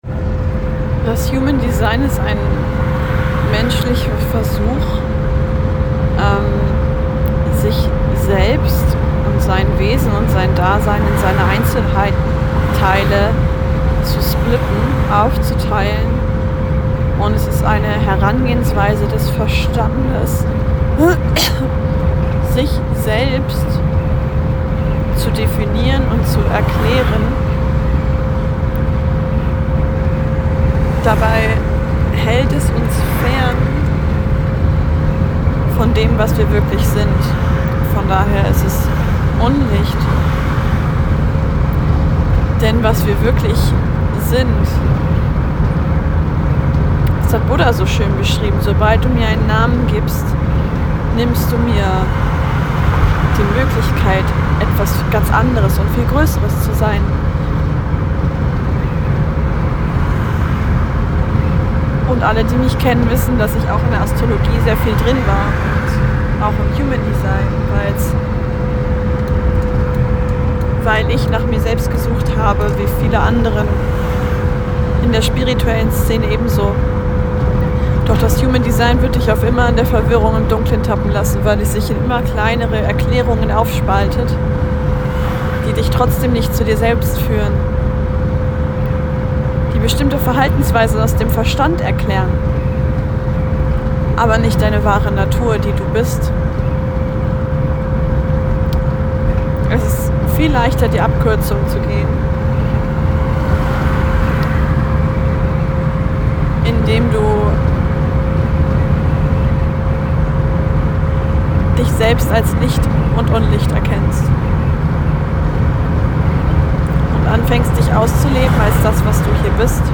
Mehr dazu erzähle ich dir in meiner heutigen Voicie aus meinem Octobus.